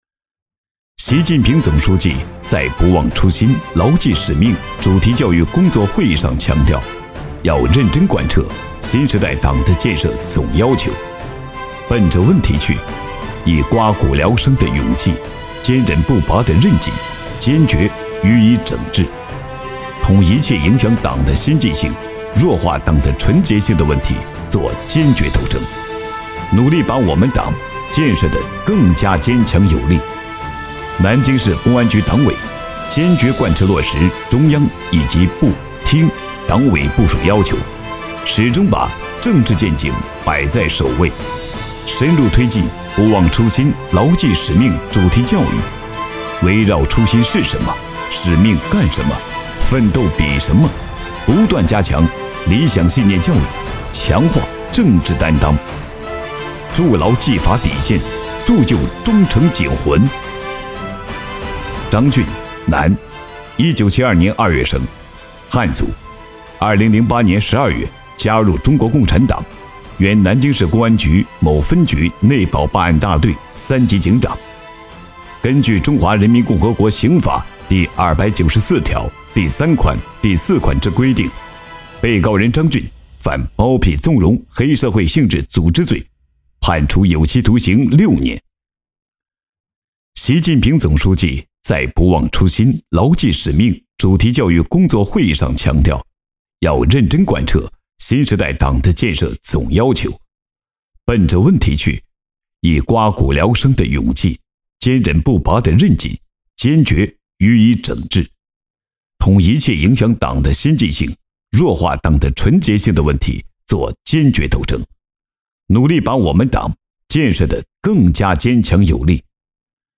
男638专题汇报配音-深度配音网
男638--汇报片-大气---法制-公安类.mp3